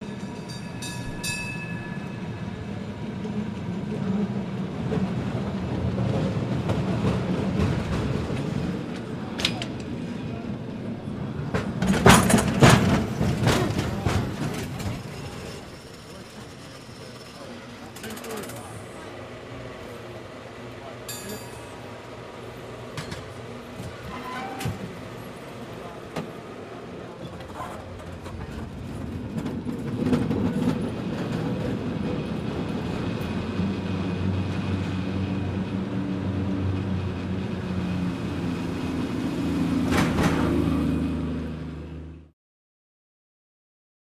Cable Car, San Francisco, In With Bells, Stop and Away, Light Walla